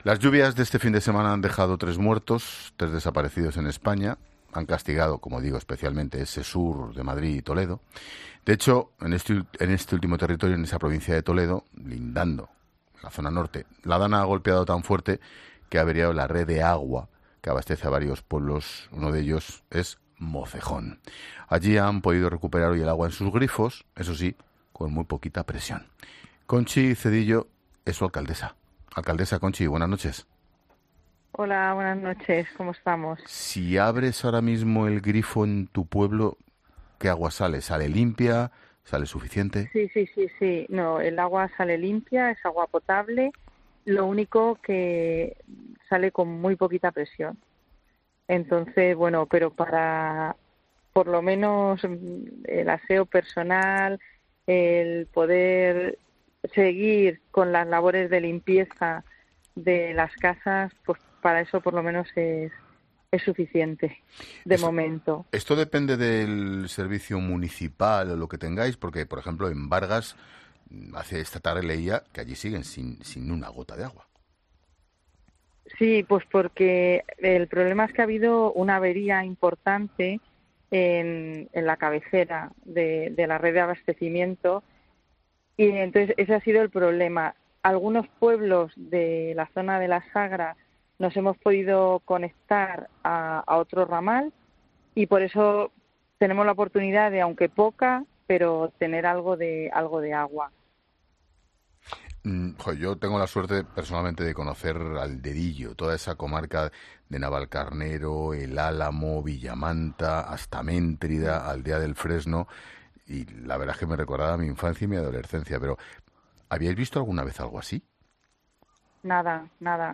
Escucha la entrevista de Expósito a la alcaldesa de Mocejón (Toledo), municipio afectado por la DANA